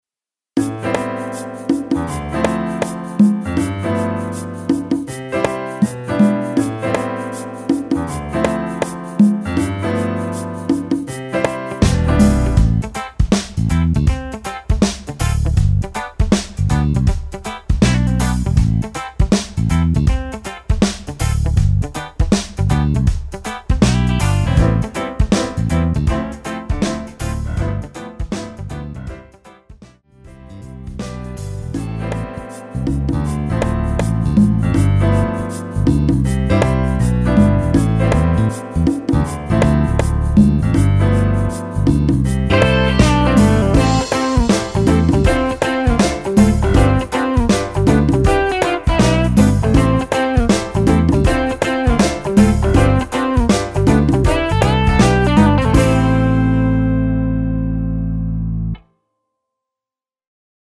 엔딩이 페이드 아웃이라 라이브 하시기 좋게 엔딩을 만들어 놓았습니다.(미리듣기 참조)
Am
앞부분30초, 뒷부분30초씩 편집해서 올려 드리고 있습니다.
중간에 음이 끈어지고 다시 나오는 이유는